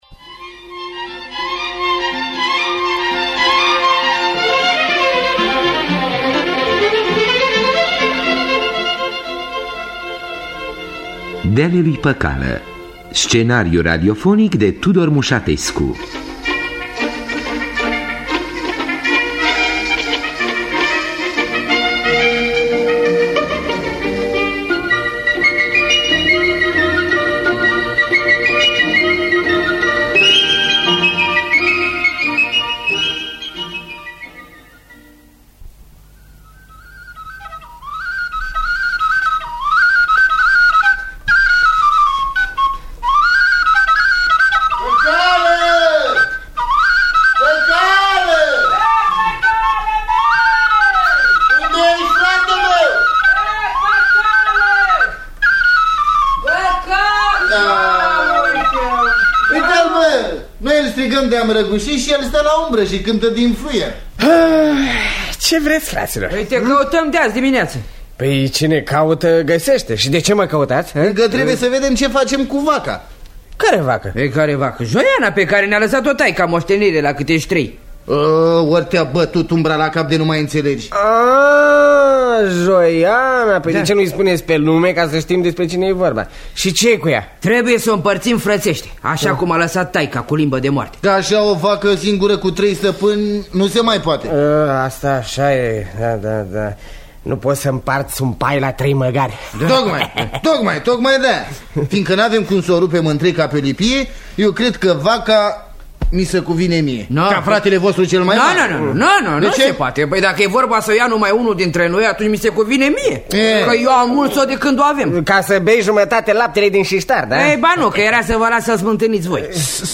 Adaptare radiofonică de Călin Gruia.